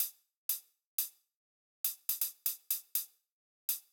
ORG Beat - Hats.wav